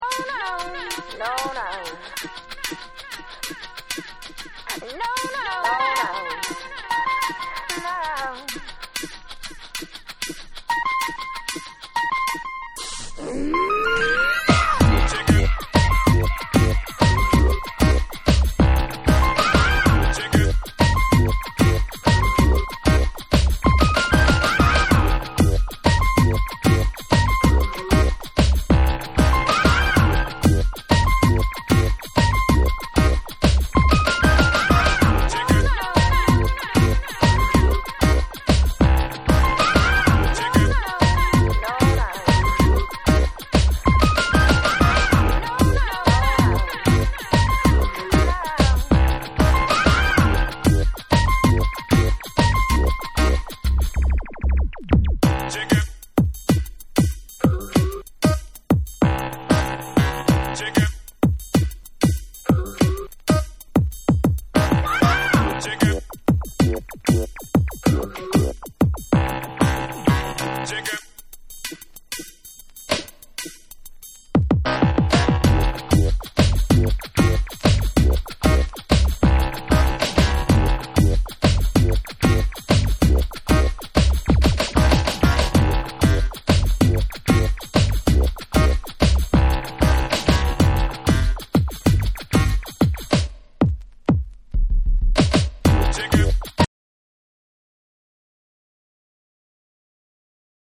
JAPANESE / REGGAE & DUB